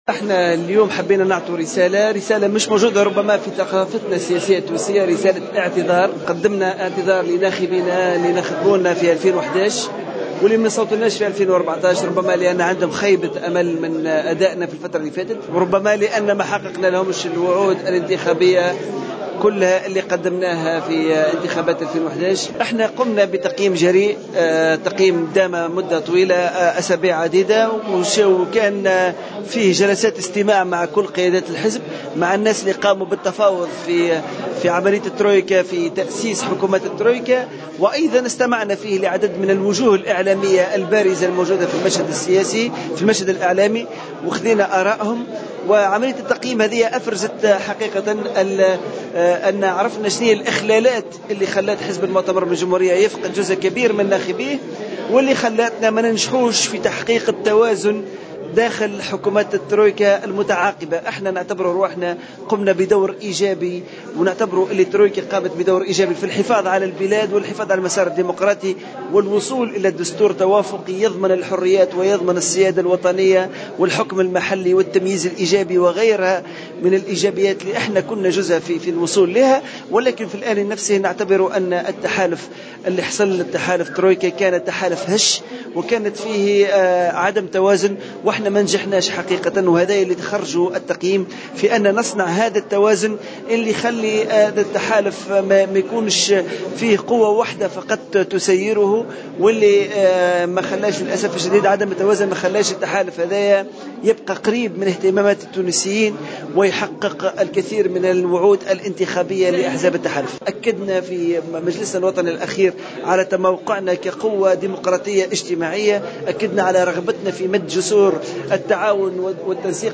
Le congrès pour la république (CPR) a tenu ce Mercredi 8 Avril une conférence de presse pour présenter les résultats du congrès national extraordinaire.